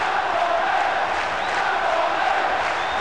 crowd3.wav